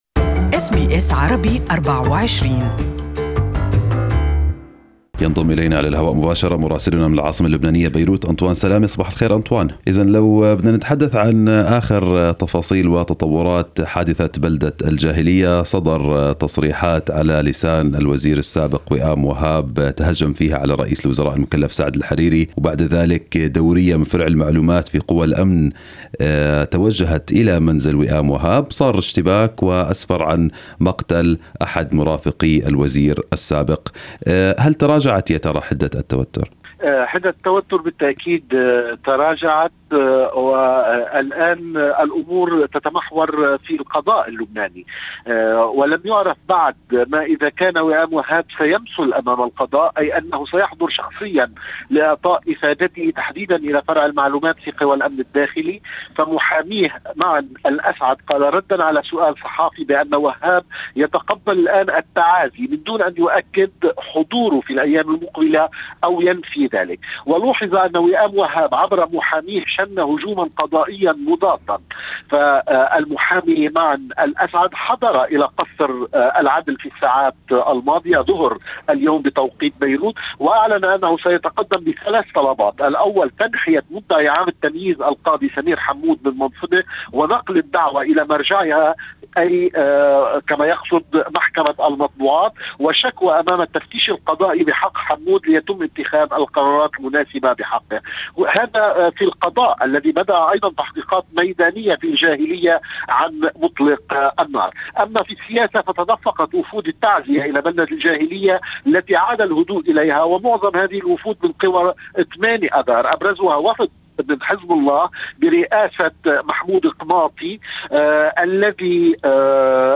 Listen to the full report from our correspondent in Beirut